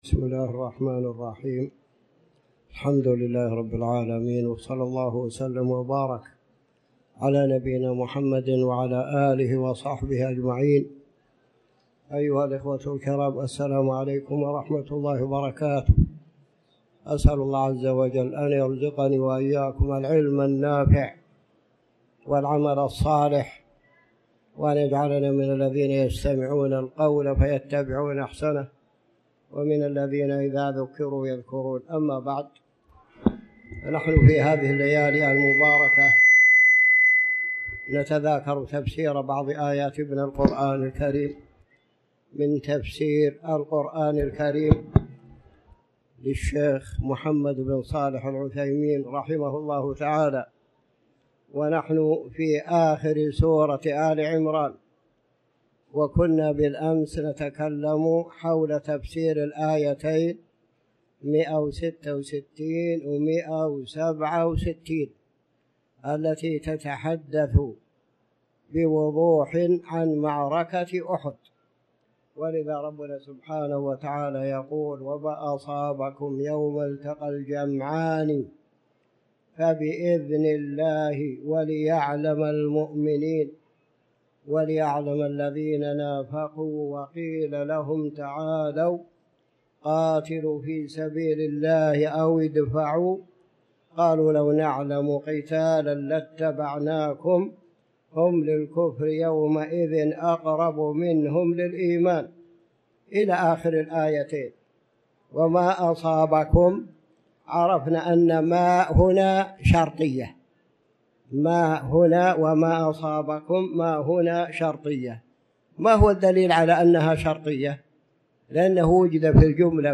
تاريخ النشر ١٨ ربيع الأول ١٤٤٠ هـ المكان: المسجد الحرام الشيخ